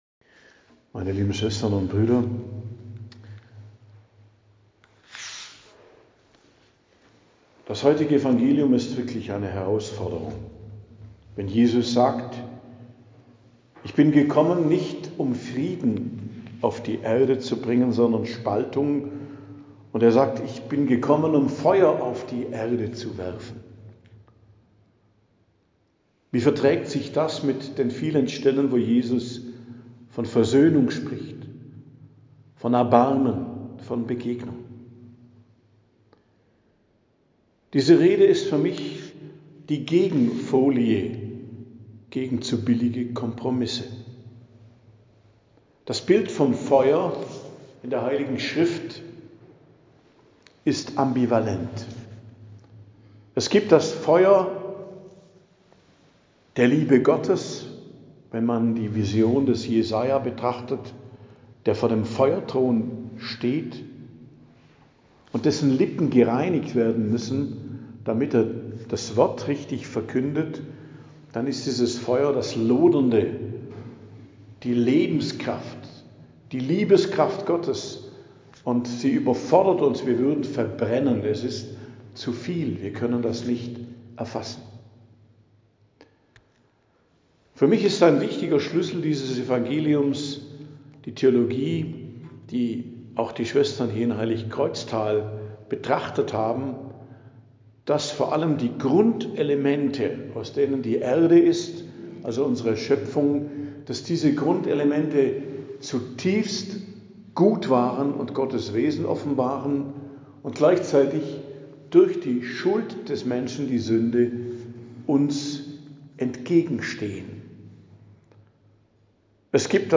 Predigt am Donnerstag der 29. Woche i.J., 23.10.2025